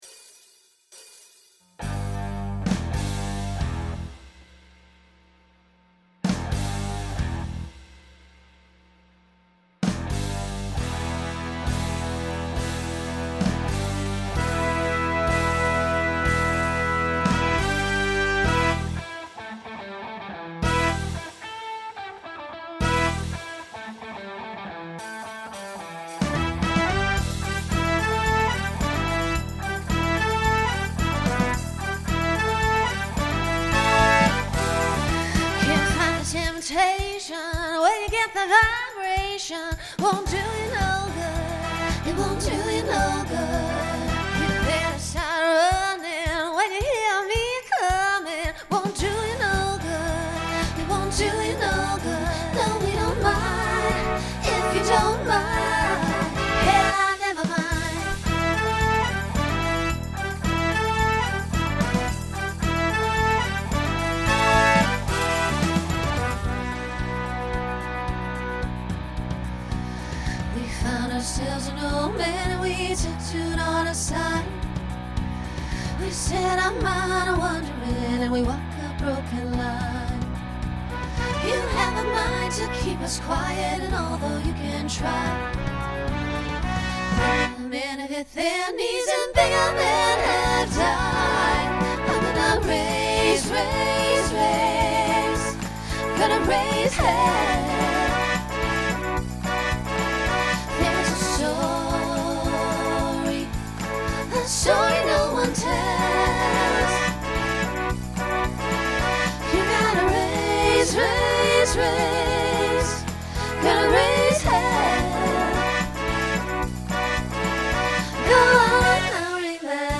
Genre Country , Rock Instrumental combo
Voicing SSA